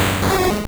Cri d'Hyporoi dans Pokémon Or et Argent.